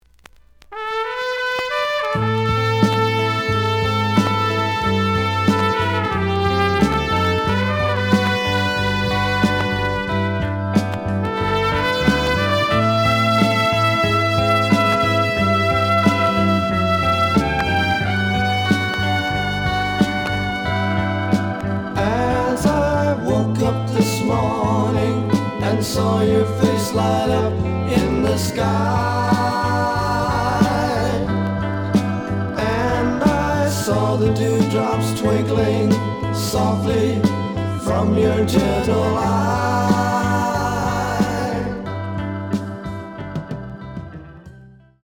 The audio sample is recorded from the actual item.
●Genre: Soul, 70's Soul
Some click noise on both sides due to scratches.